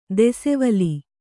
♪ desevali